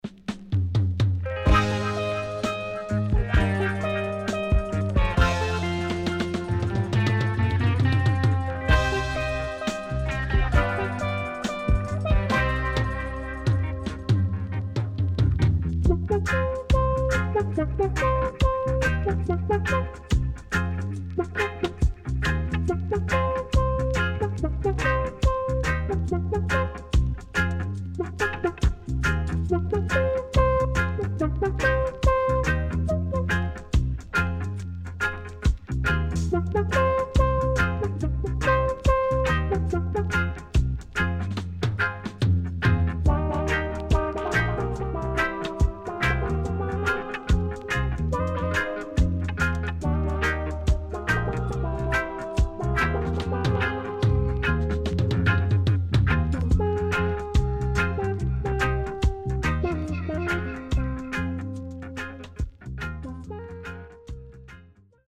HOME > DUB
SIDE A:少しチリノイズ入りますが良好です。